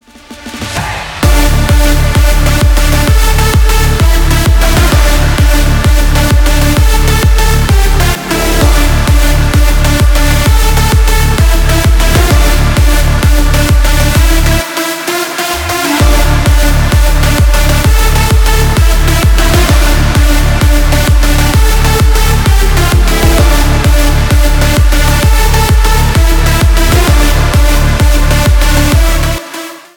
Электроника
клубные # громкие # без слов